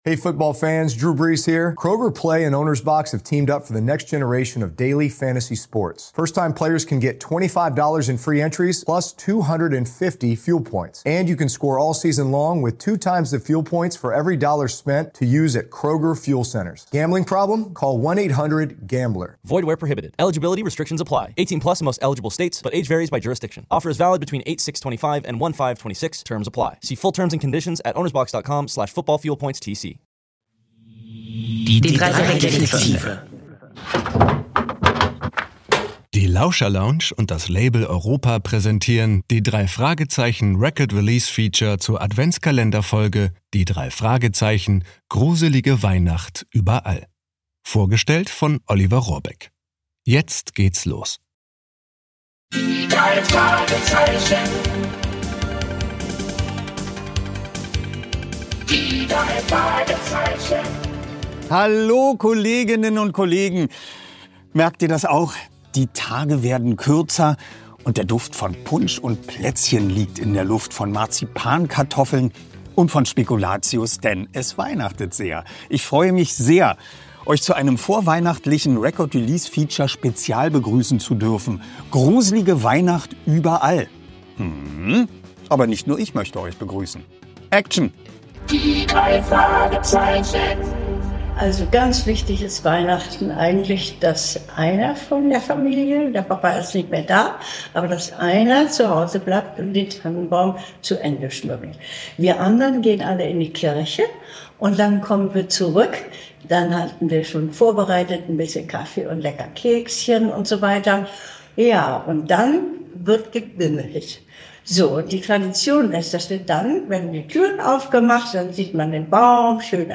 Freut euch auf Hörspielausschnitte, Lesesequenzen aus dem Buch von Christoph Dittert und spezialgelagerte Sonder-Weihnachtsgrüße.